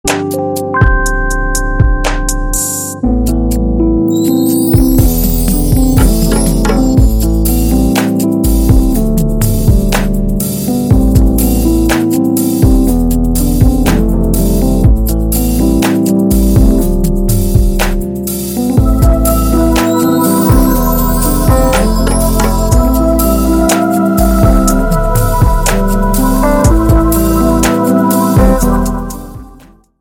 POP  (02.06)